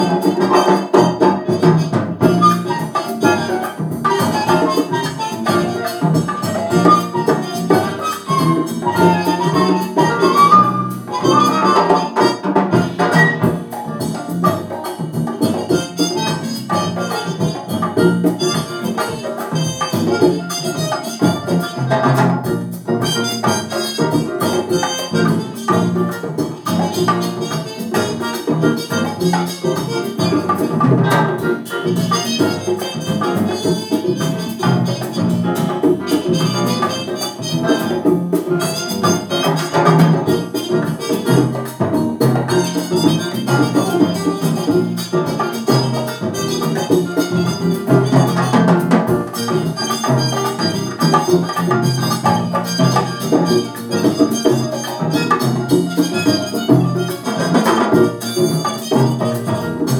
So it was time for Windows 11 and now the digital recording was overloading, saturating, clipping, whatever you want to call it when the emerald-green and amber-yellow display turns bright ruby-red.
Well, it took some monkeying around to figure out that there was some missing driver and the laptop was bypassing all my expensive, elegant, eloquent electronics and using the little microphone it has for speech communication. Review of the resulting digital file was as terrible as one would expect. The immediate, obvious symptom was no stereo separation.